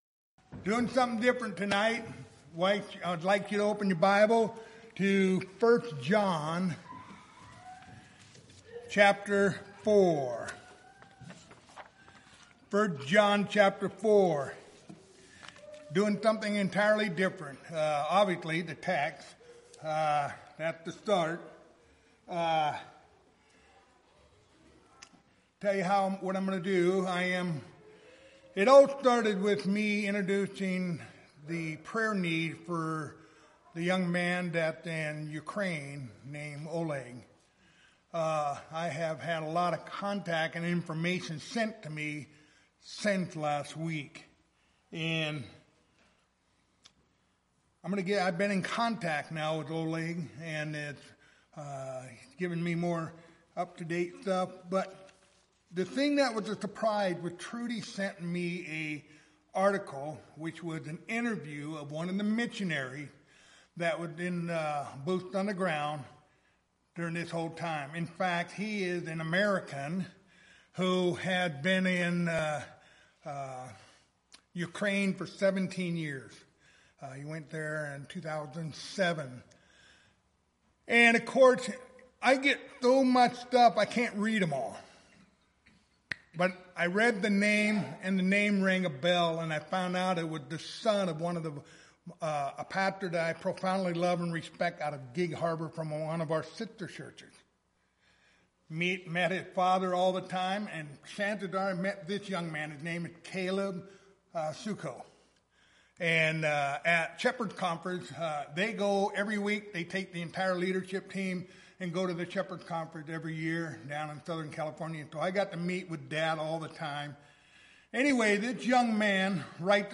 1 John 4:14-18 Service Type: Wednesday Evening Topics